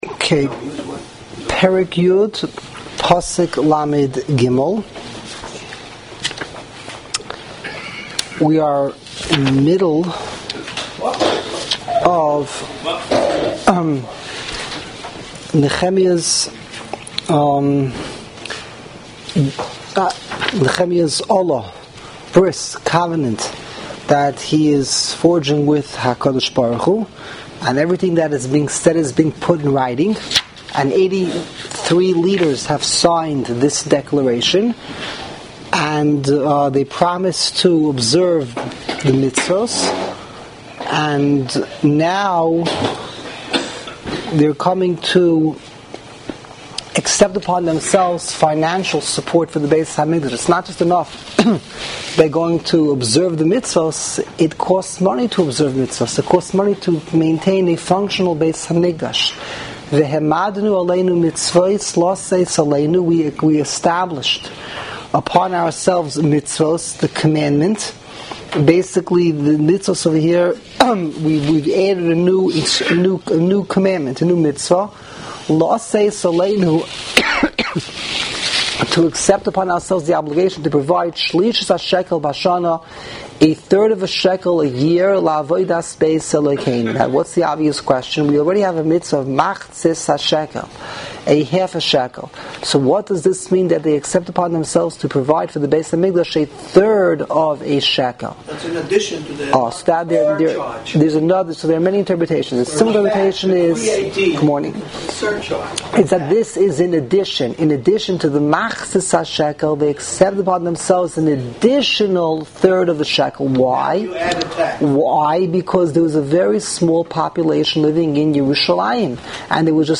Live Daily Shiurim